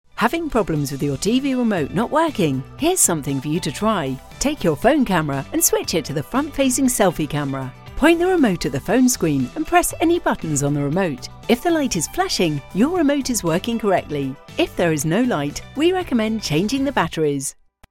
English (British)
Commercial, Warm, Versatile, Friendly, Corporate
Audio guide